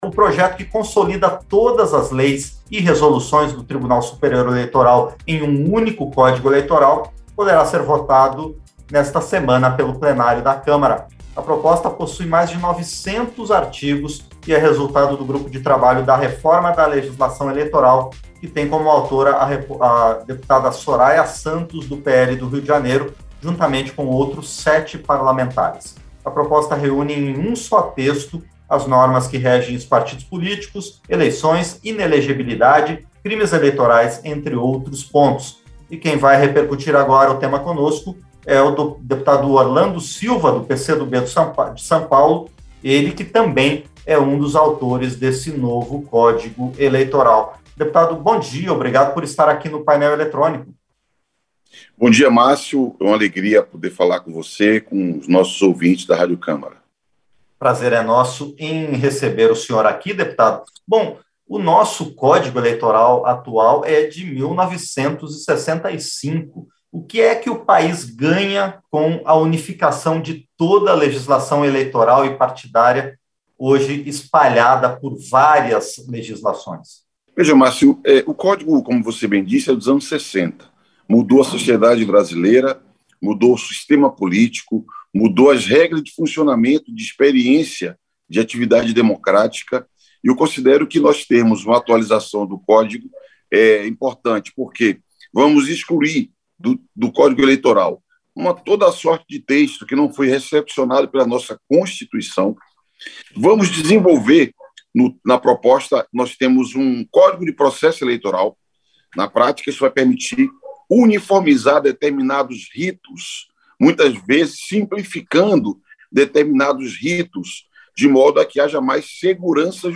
Entrevista - Dep. Orlando Silva (PCdoB-SP)